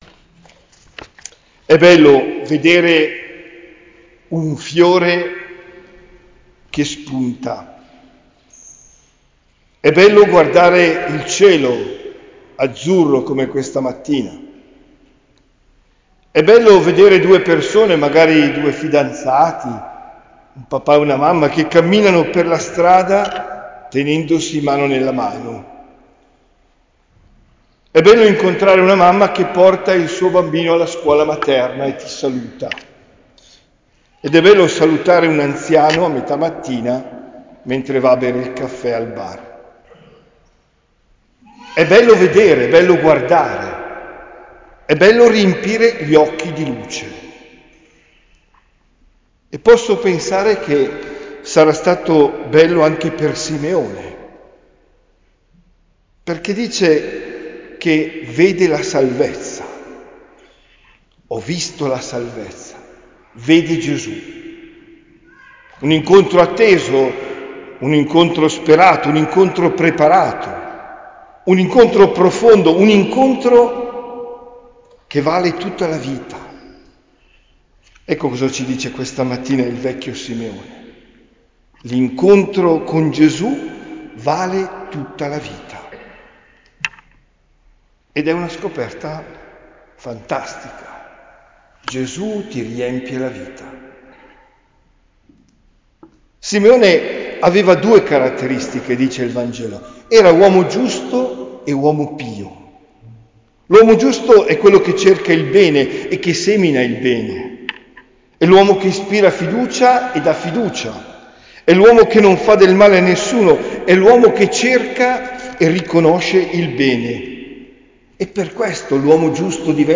OMELIA DEL 2 FEBBRAIO 2025